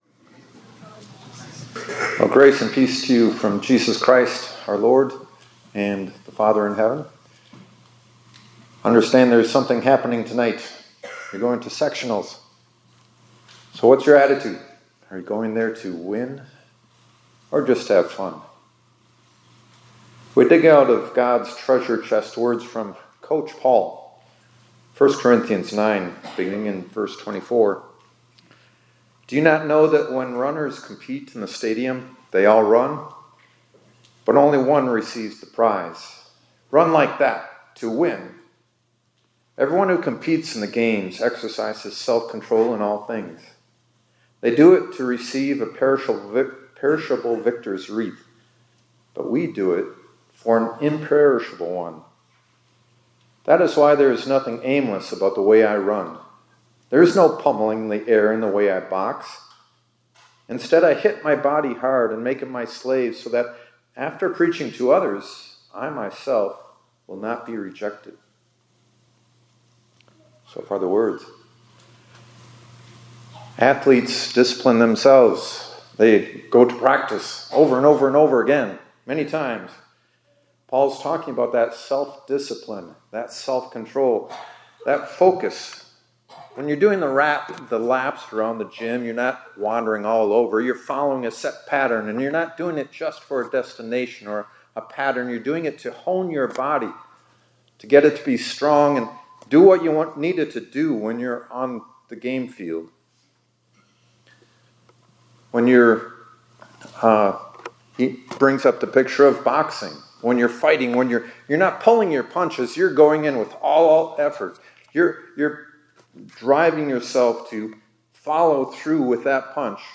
2025-03-06 ILC Chapel — Train to Win